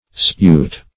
Search Result for " spute" : The Collaborative International Dictionary of English v.0.48: Spute \Spute\ (sp[=u]t), v. t. [Abbrev. from dispute.] To dispute; to discuss.